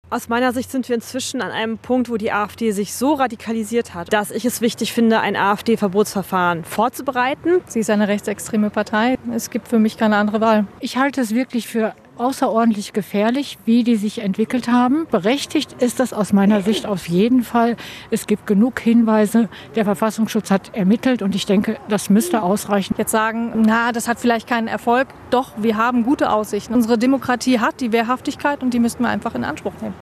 Stimmen von Besuchern der Veranstaltung im gut besuchten Theater an der Volme am Dienstag Abend. Unter anderem stand die Fraktionschefin der Grünen im Landtag - Verena Schäffer - Rede und Antwort.
afd-verbot---collage.mp3